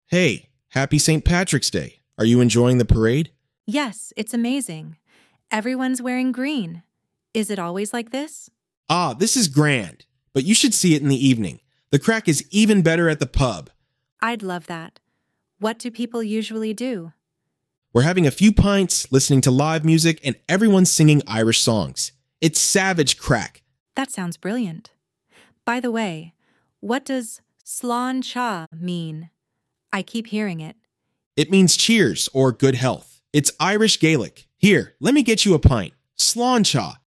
Descoperă expresii autentice irlandeze, vocabular de St. Patrick's Day, gramatică cu Present Continuous și un fun fact uimitor: Sfântul Patrick nu era irlandez! Dialog la parada din Dublin.